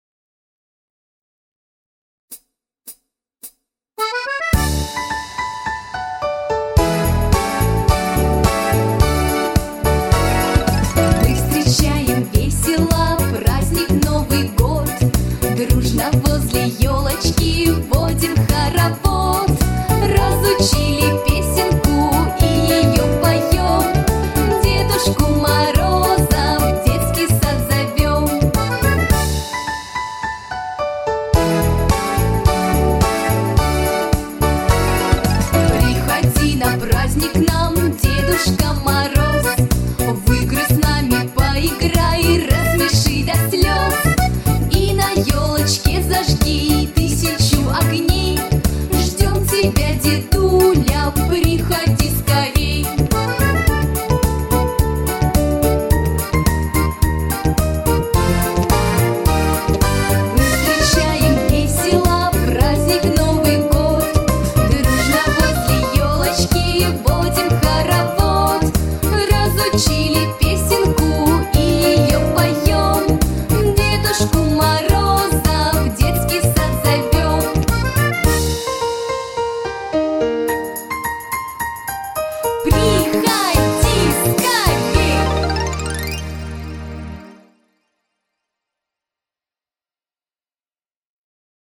Новогодние песни